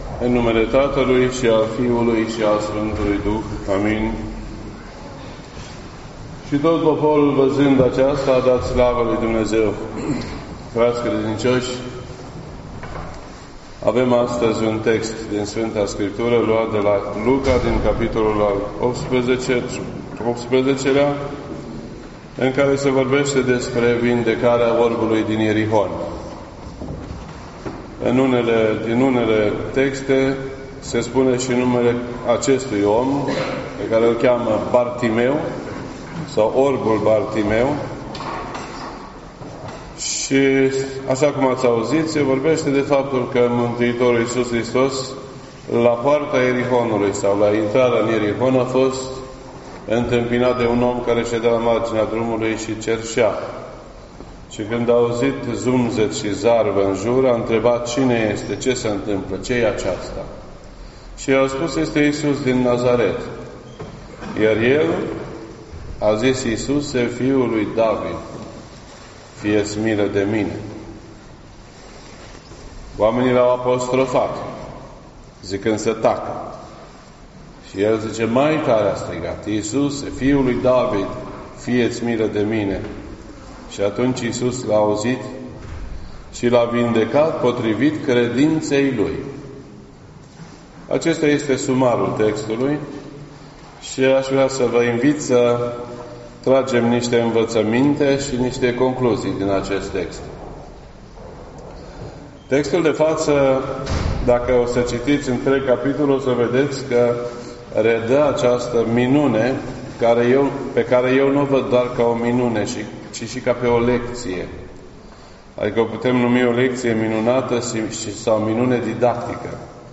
This entry was posted on Sunday, January 27th, 2019 at 12:23 PM and is filed under Predici ortodoxe in format audio.